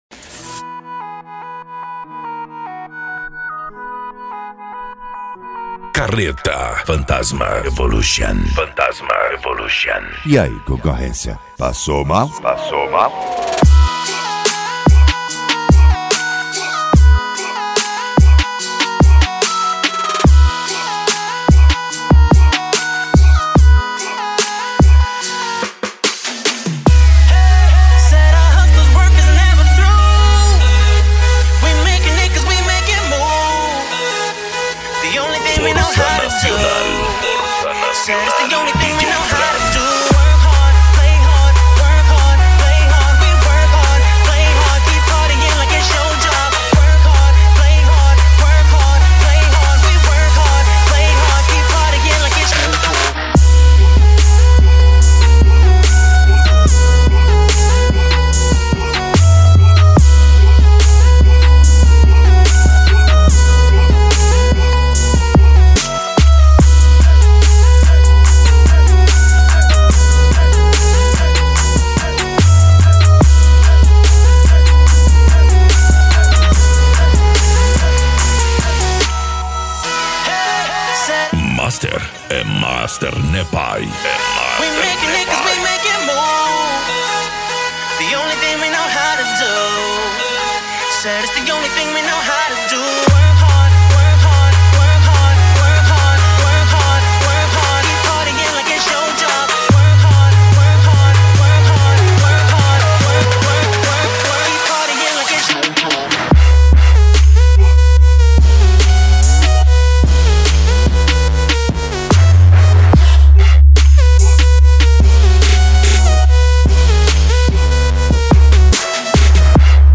Bass
Deep House
Eletronica
Mega Funk